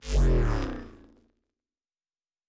Shield Device 6 Stop.wav